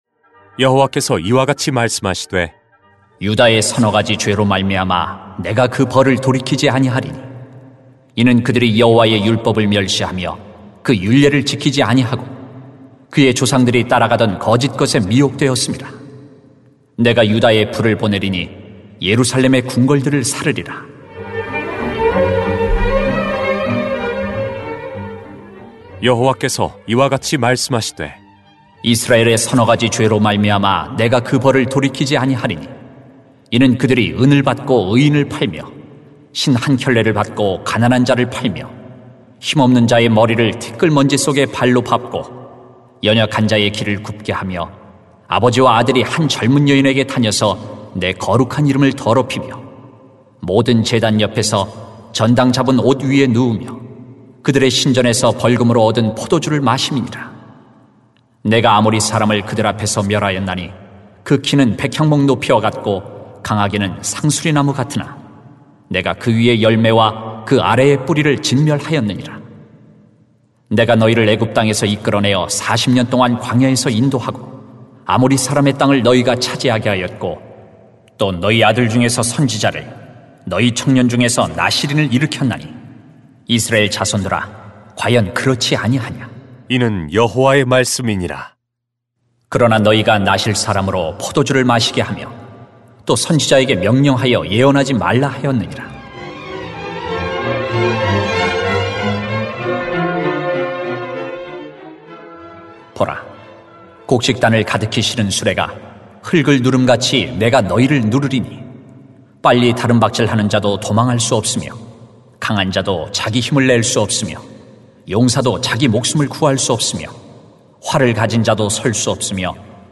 [암 2:4-16] 무엇이 더 중요할까요? > 새벽기도회 | 전주제자교회